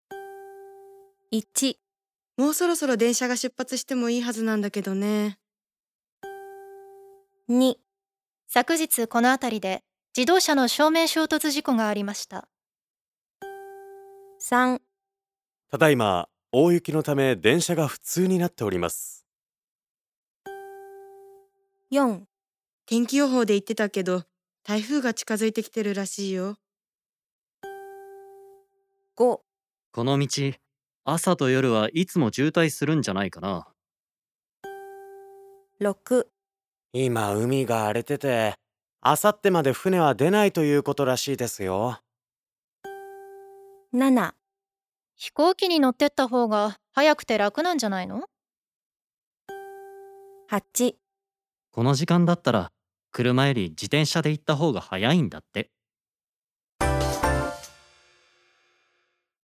• Conversation
Lively CD recordings vividly reenact realistic conversations that keep you engaged.